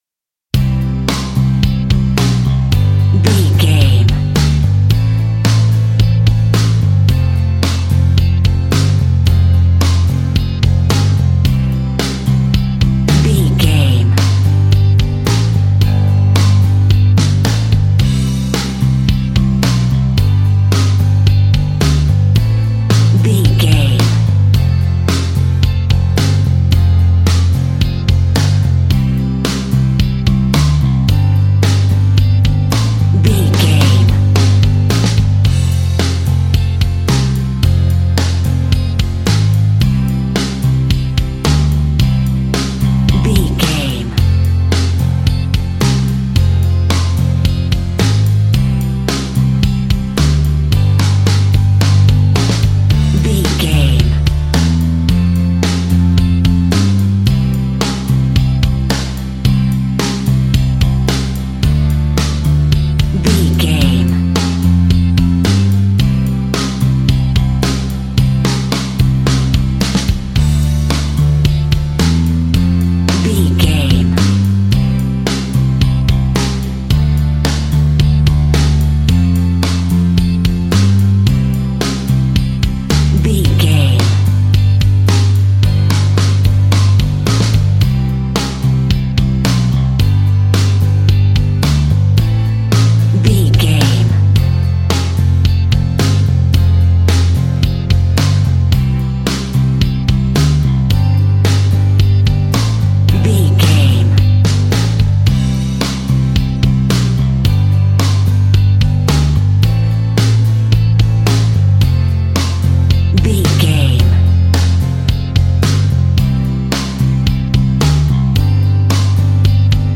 Ionian/Major
calm
melancholic
happy
energetic
optimistic
smooth
uplifting
electric guitar
bass guitar
drums
pop rock
indie pop
instrumentals
organ